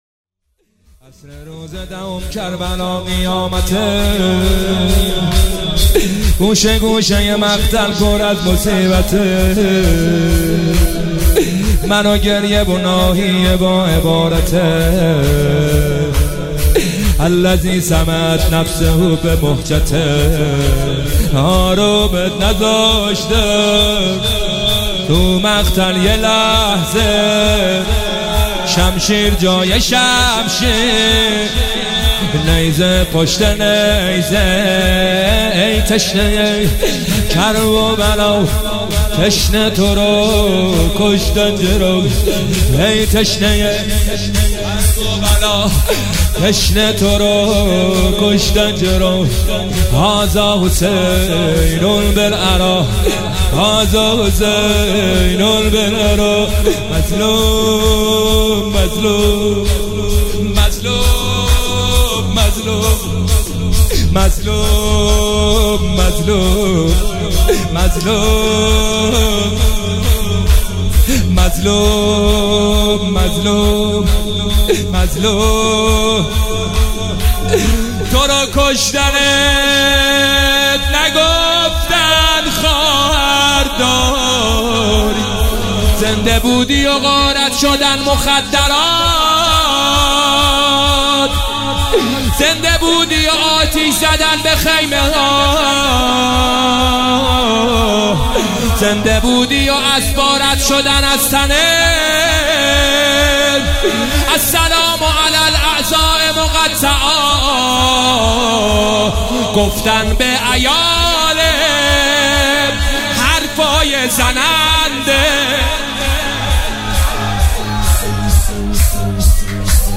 عصر روز دهم کربلا قیامته گوشه گوشه مقتل پر از مصیبته - شور